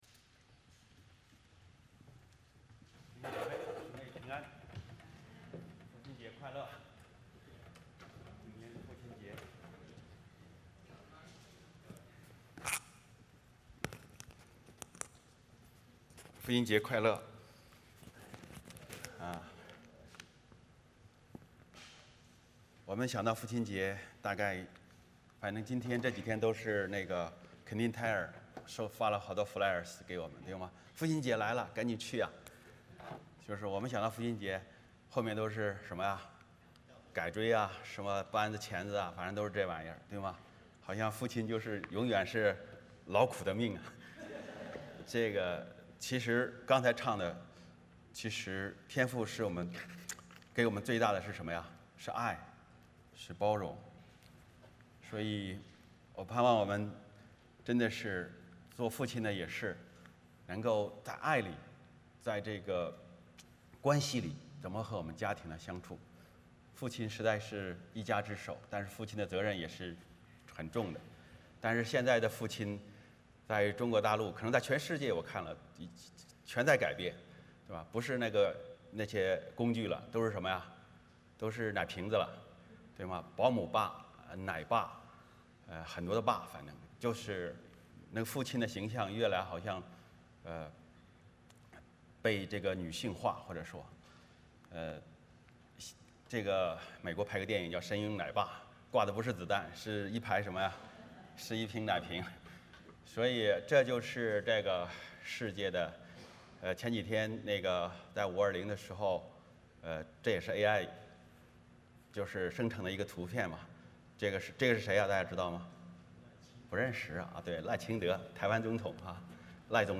Passage: 创世记 2:18-25 Service Type: 主日崇拜 欢迎大家加入我们的敬拜。